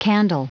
Prononciation du mot candle en anglais (fichier audio)
Prononciation du mot : candle